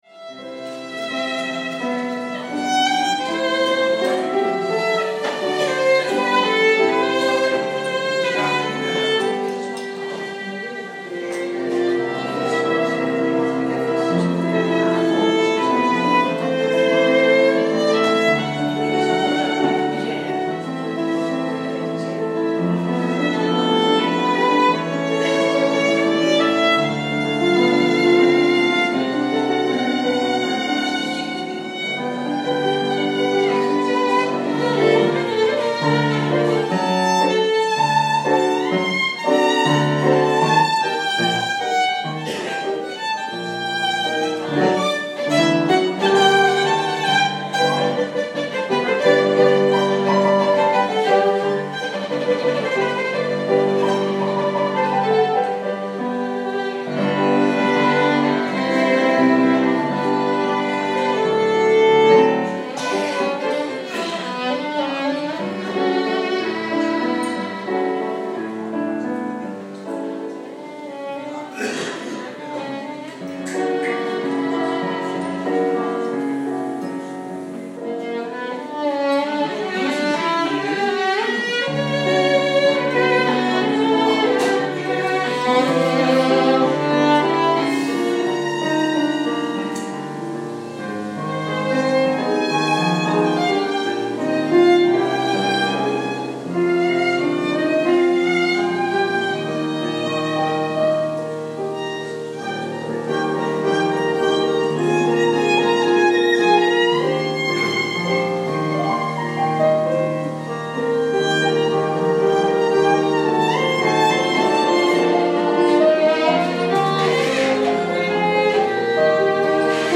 Het is altijd fijn om in verzorgingshuizen te kunnen spelen. In het begin was het nog wat onrustig maar gaandeweg kon iedereen zich verliezen in de muziek.
Hierbij een compilatie van de muziek die we vandaag hebben gespeeld.